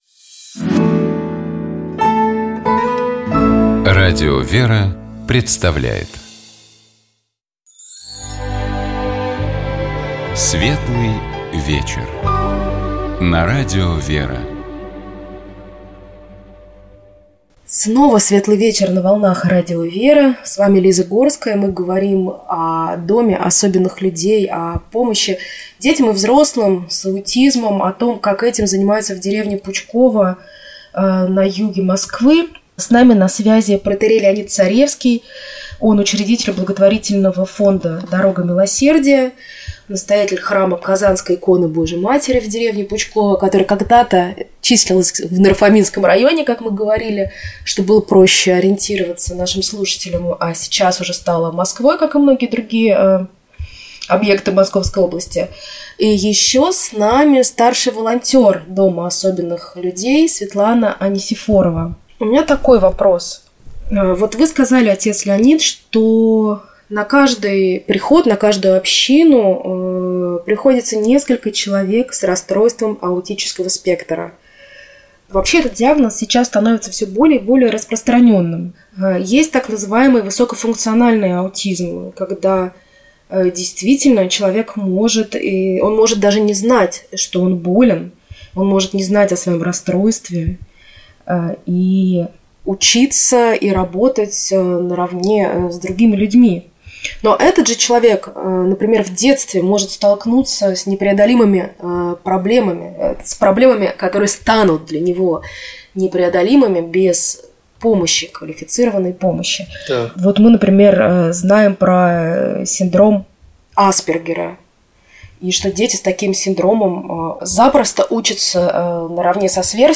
В эфире радио «Вера», программа «Светлый вечер».